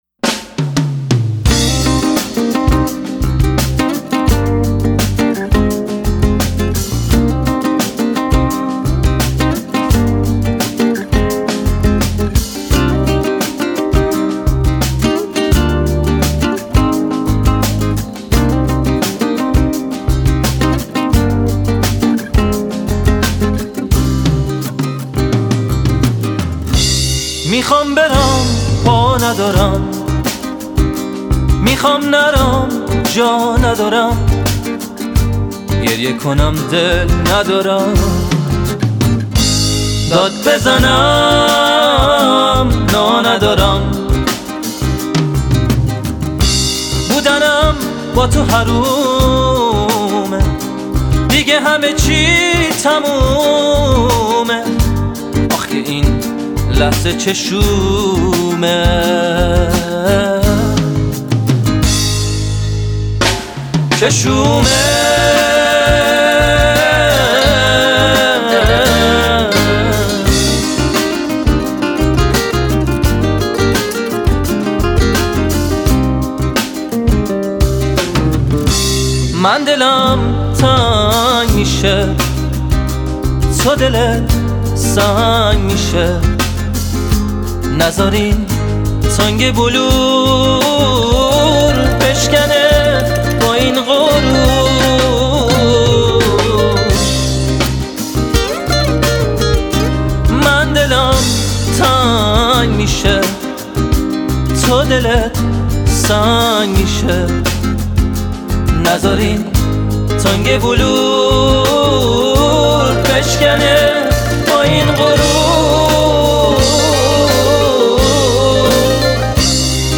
به سبک پاپ است.